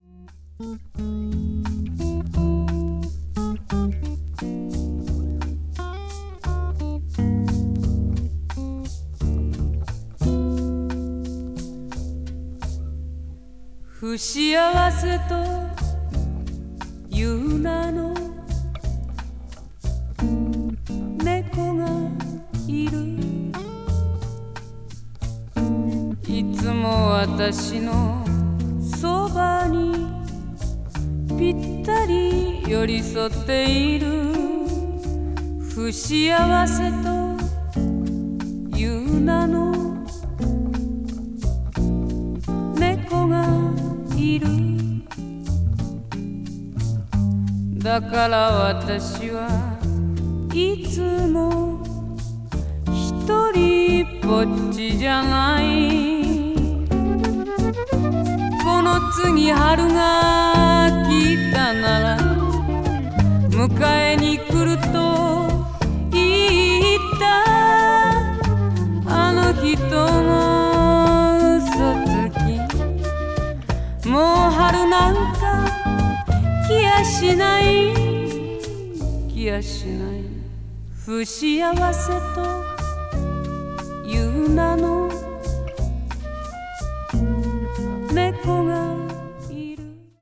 blues   blues rock   folk   folk rock   jazz vocal   soul